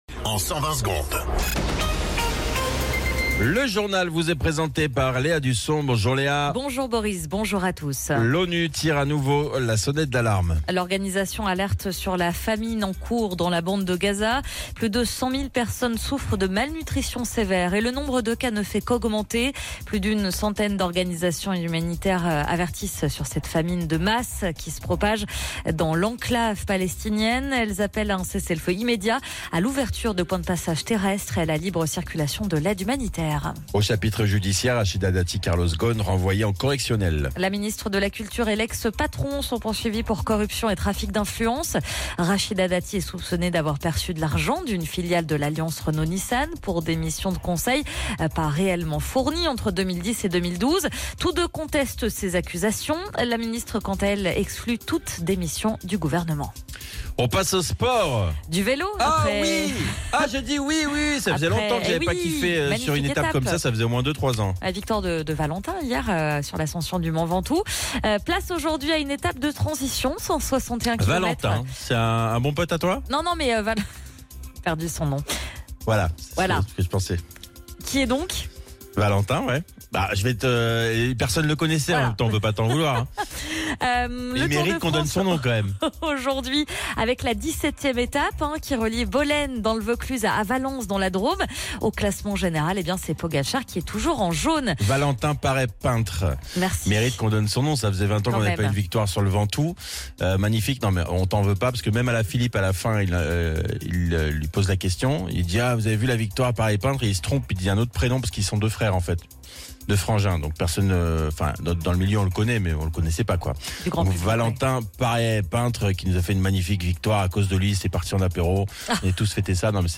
Flash Info National 23 Juillet 2025 Du 23/07/2025 à 07h10 .